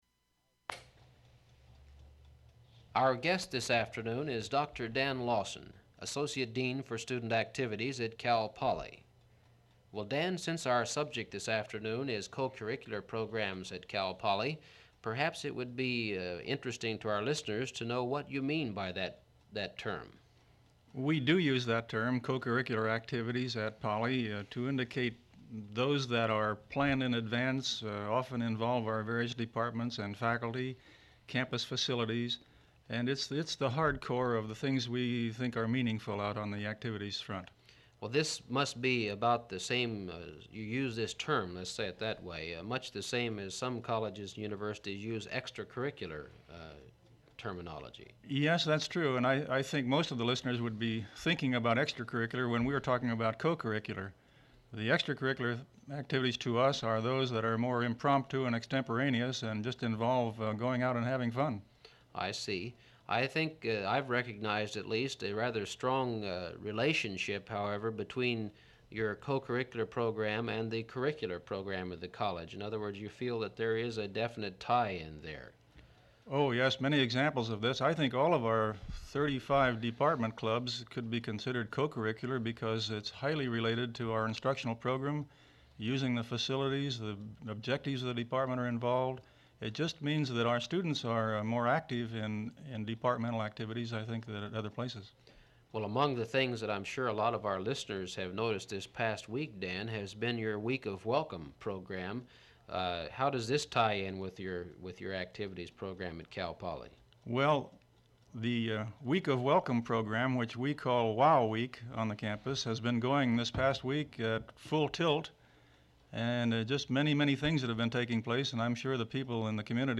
• Open reel audiotape